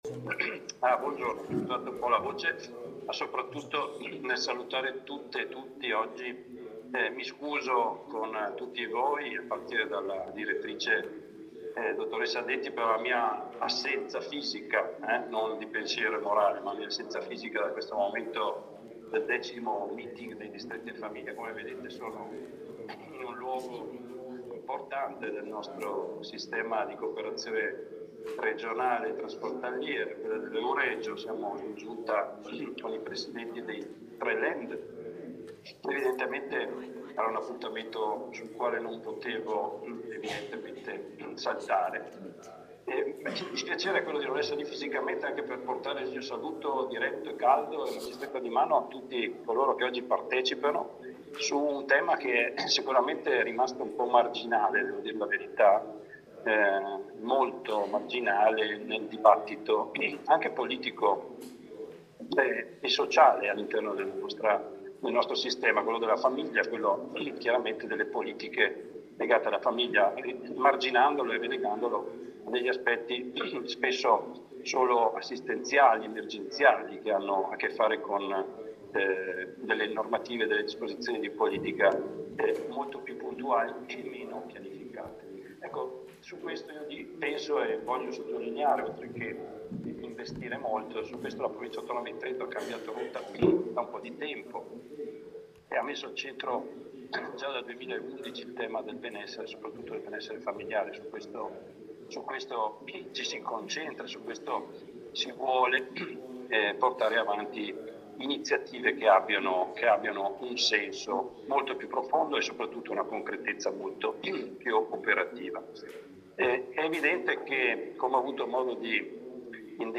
All’evento ha partecipato il vicepresidente della Provincia autonoma di Trento Achille Spinelli
Audio_intervento_vicepresidente_Pat_Achille_Spinelli.mp3